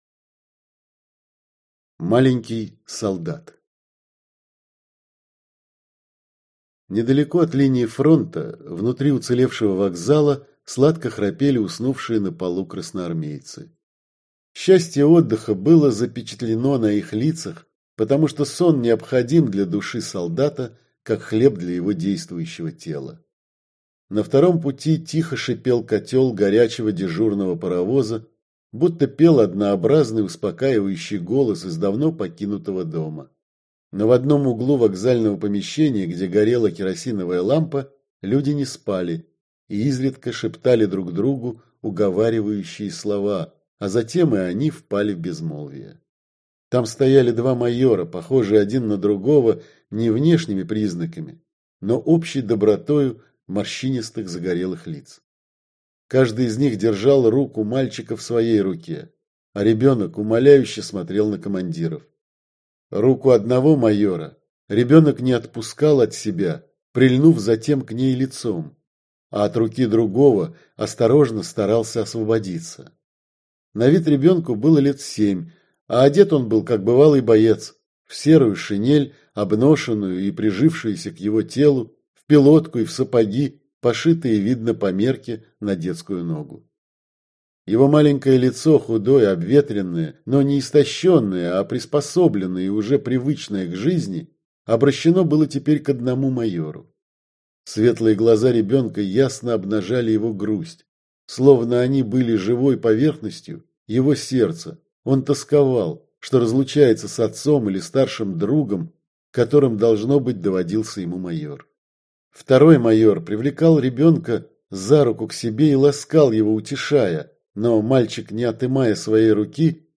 Аудиокнига Возвращение - купить, скачать и слушать онлайн | КнигоПоиск